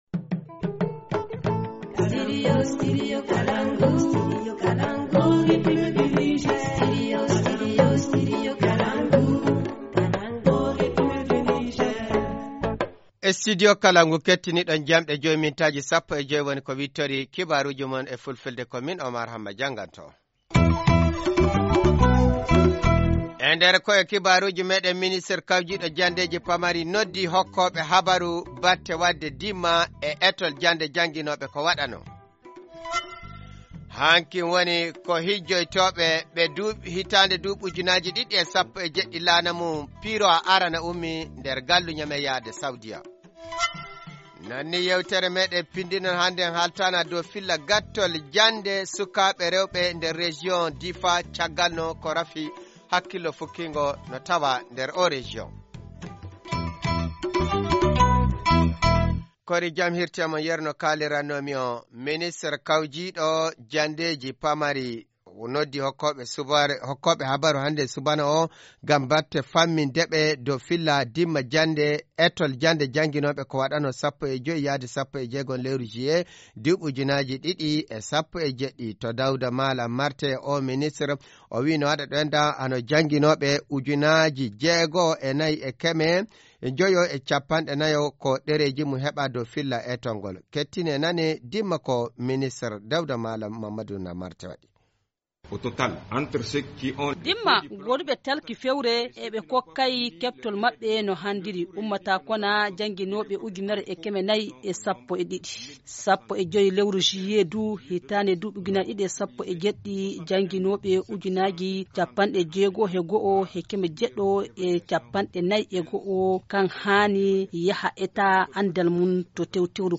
Journal du 11 août 2017 - Studio Kalangou - Au rythme du Niger